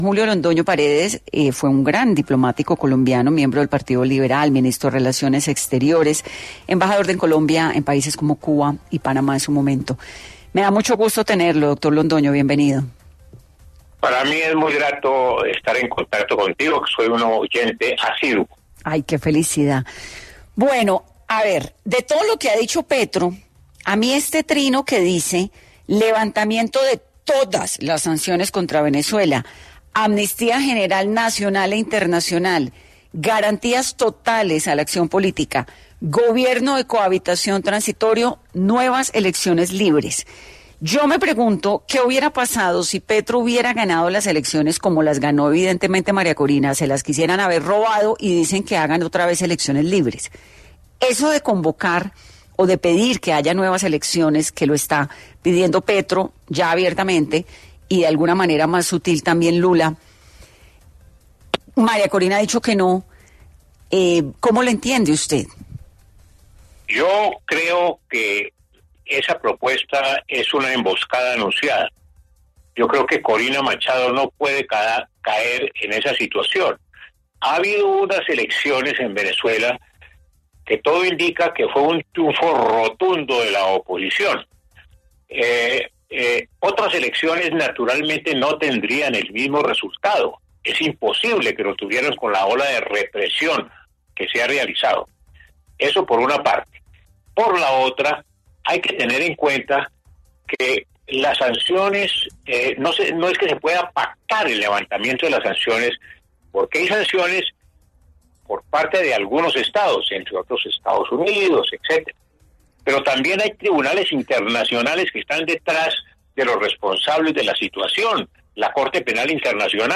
En 10AM Hoy por Hoy de Caracol Radio estuvo Julio Londoño Paredes, diplomático colombiano y exministro de Relaciones Exteriores, para hablar del mensaje del presidente Petro sobre nuevas elecciones en Venezuela.